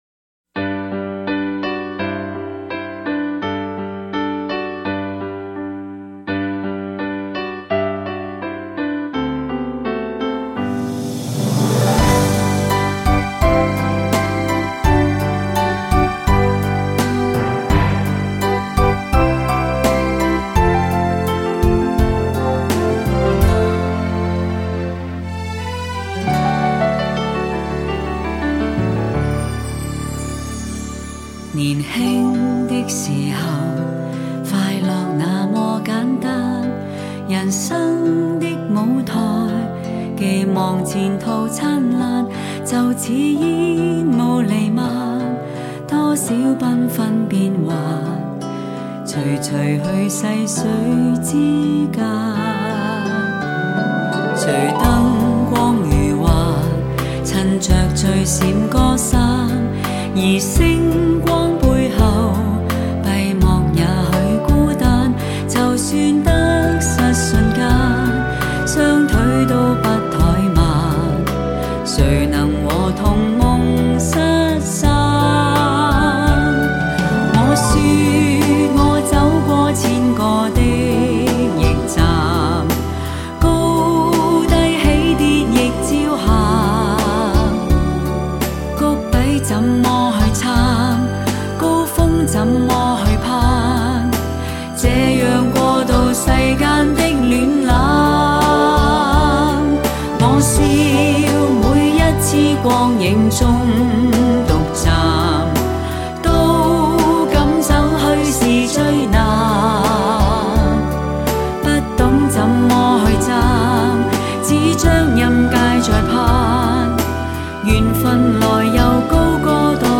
音色更接近模拟(Analogue)声效
强劲动态音效中横溢出细致韵味